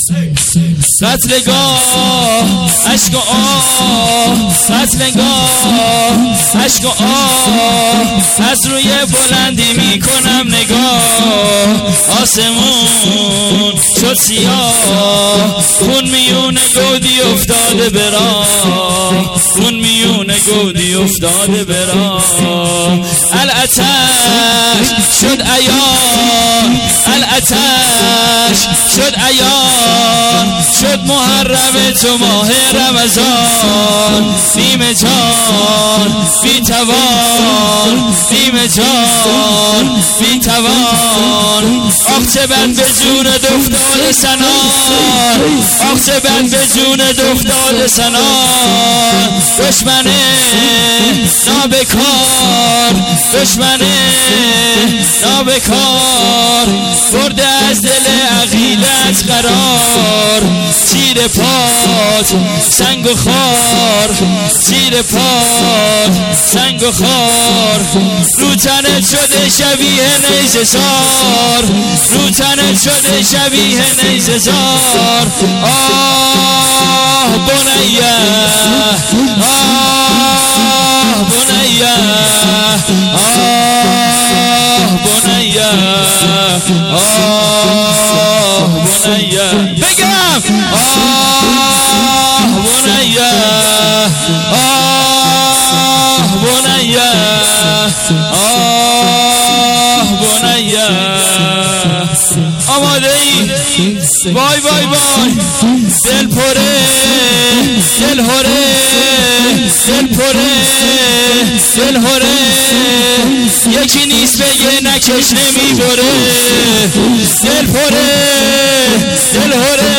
هیئت عاشورا قم
هیئت عاشورا-قم